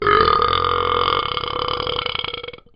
Talking Ben Burp 2 Sound Effect Free Download
Talking Ben Burp 2